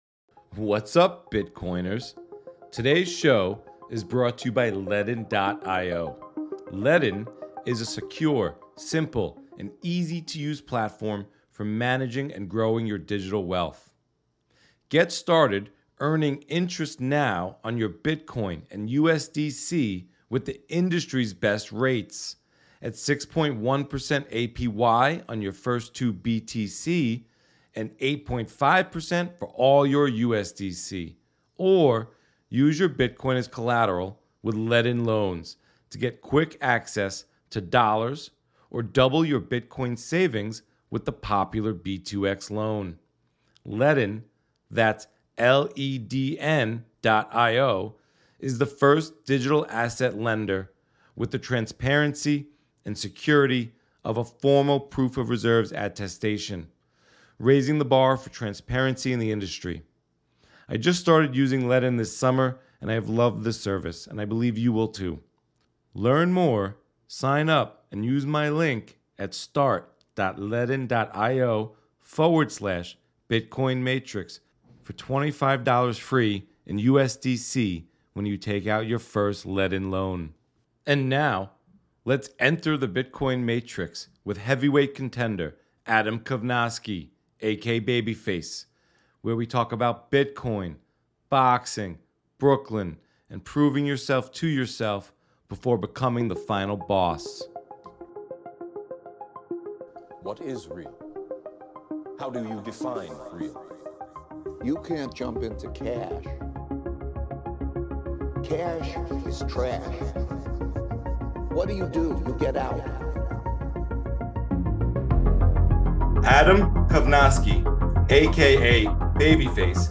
In this chat with heavyweight boxing contender Adam Kownacki we talk about his time growing up in the Polish neighborhood of Greenepoint, Brooklyn, how he got into boxing, experiencing his first loss, his upcoming rematch with Robert Helenius in Las Vegas this October and proving yourself.